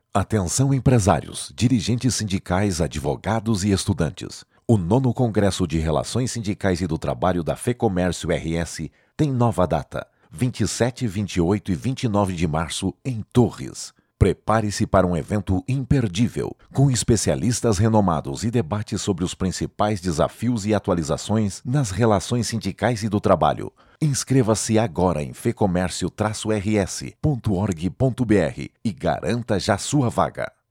Comunicado: